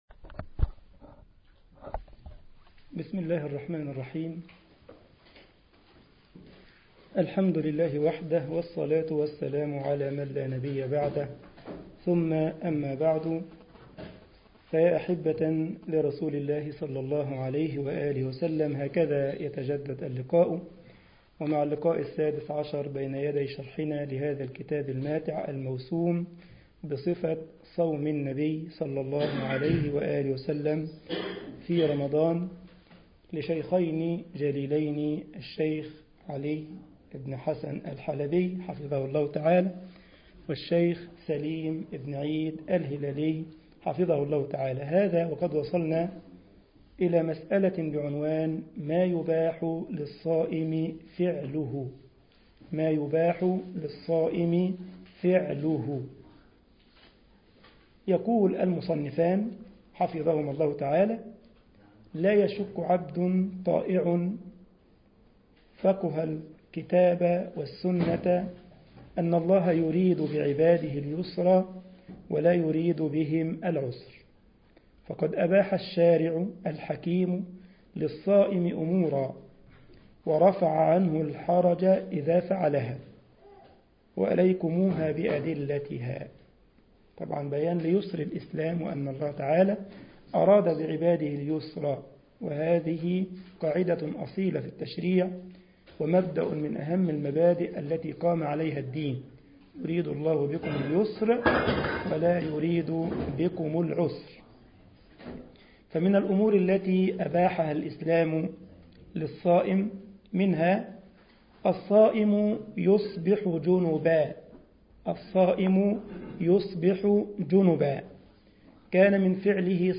مسجد الجمعية الإسلامية بالسارلند ـ ألمانيا